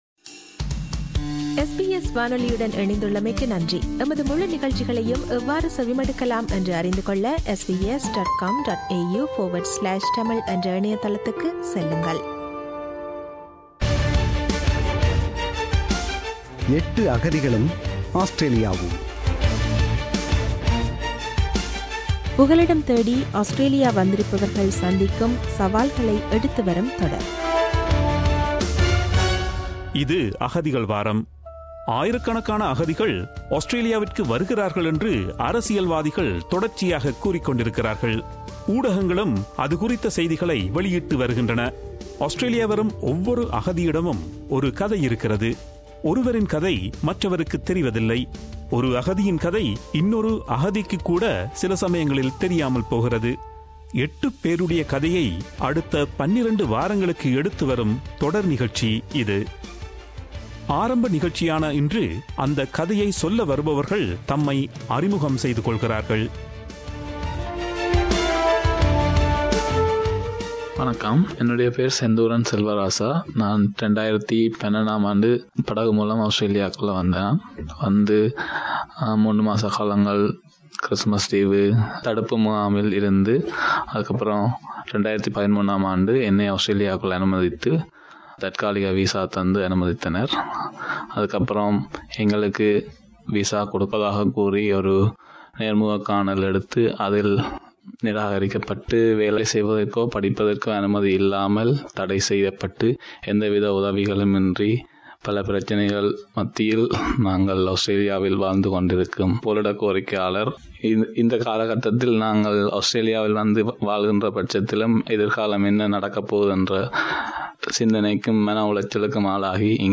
புகலிடம் தேடி ஆஸ்திரேலியா வந்திருப்பவர்கள் சந்திக்கும் சவால்கள் என்ன, சமூகத்தில் எதிர்கொள்ளும் தடைகள் எவை போன்ற பல விடயங்களை அவர்கள் பார்வையில் நேயர்களுக்கு எடுத்துவரும் தொடர் நிகழ்ச்சி. இத் தொடரின் முதல் நிகழ்ச்சியில், தொடரில் பங்கு கொள்பவர்கள் தம்மை அறிமுகப்படுத்துகிறார்கள்.